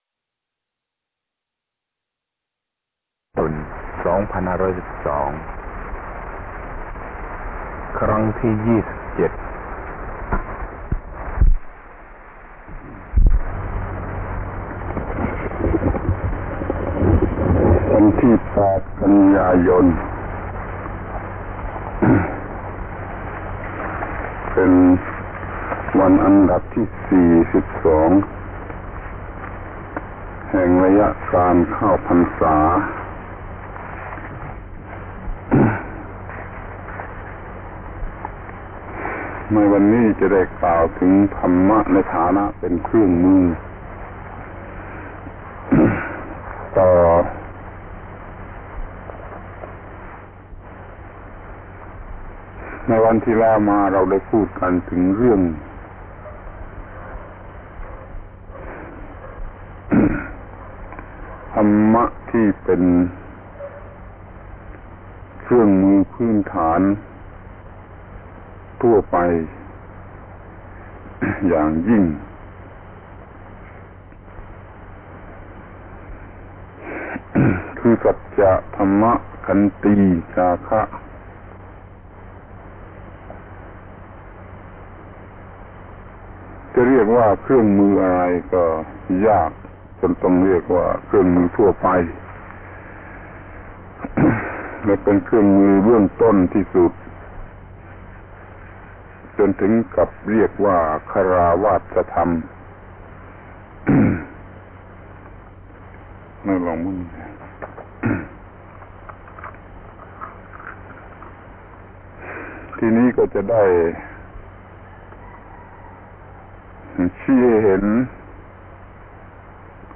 อบรมพระนวกะในพรรษา